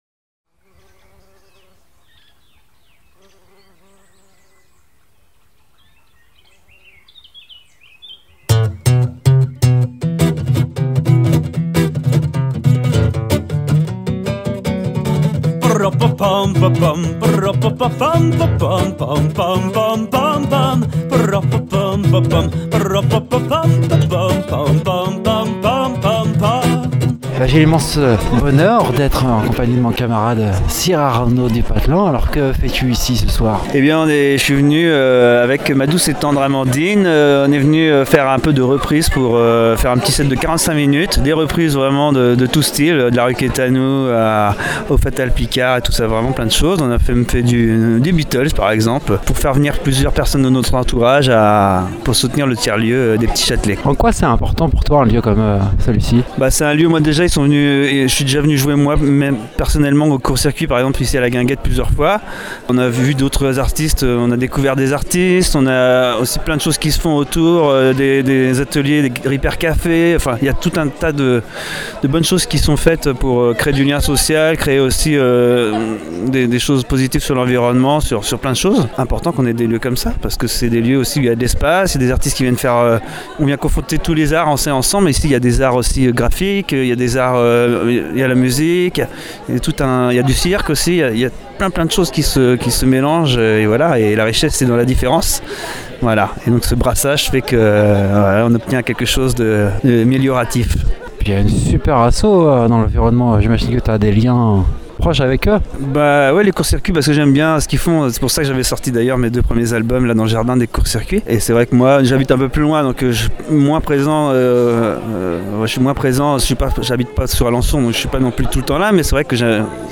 Dans cette interview réalisée aux Petits Châtelets à Alençon lors du Grand Chahut